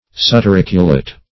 Subturriculate \Sub`tur*ric"u*late\